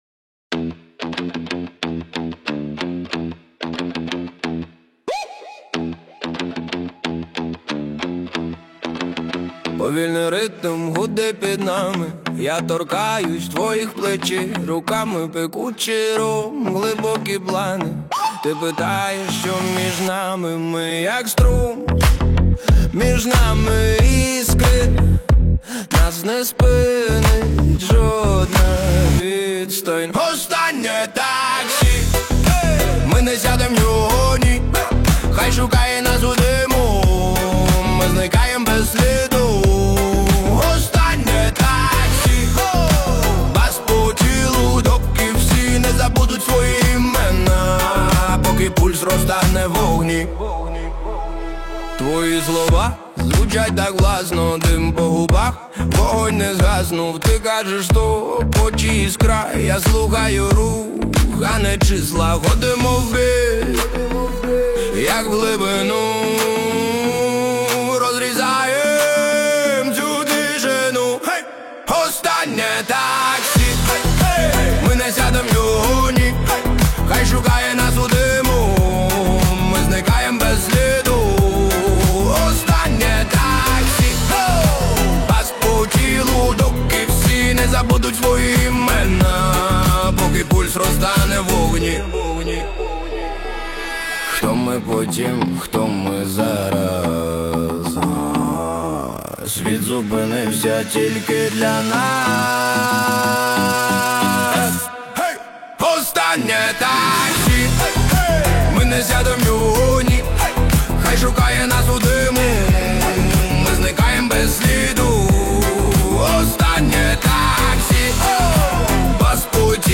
Жанр: Електронна